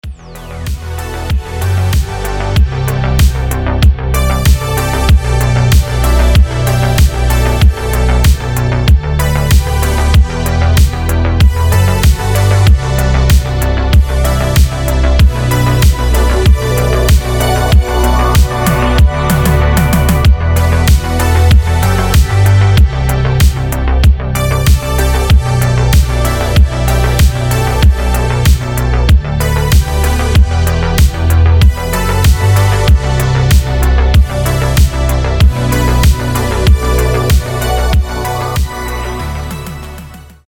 красивые
Electronic
без слов
медленные
космические
synthwave
Мечтательная диско мелодия для звонка